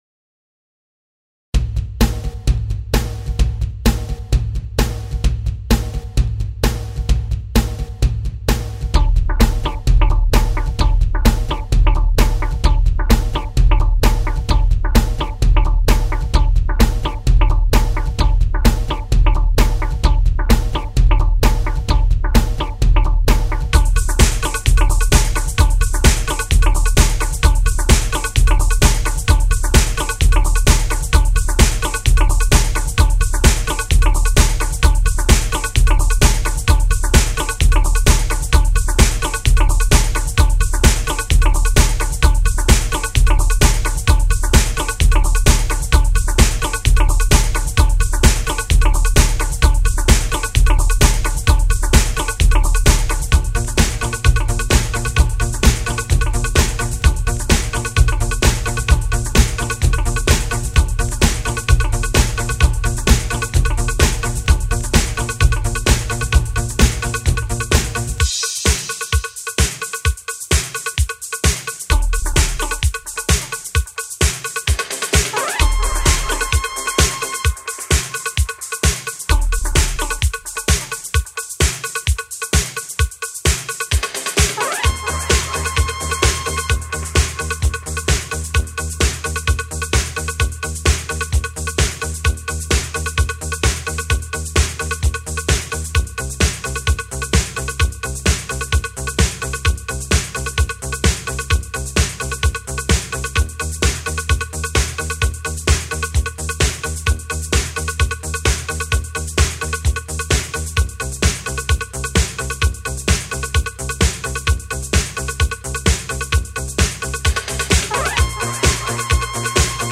danceable tracks